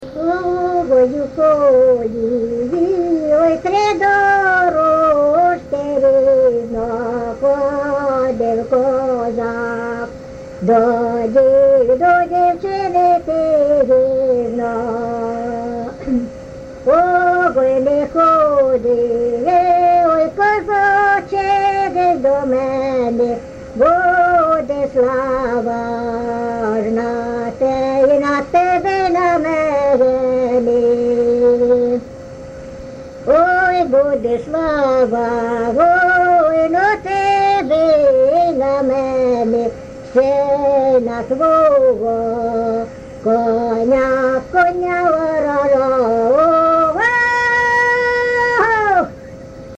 ЖанрПісні з особистого та родинного життя
Місце записус. Ярмолинці, Роменський район, Сумська обл., Україна, Слобожанщина